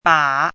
chinese-voice - 汉字语音库
ba3.mp3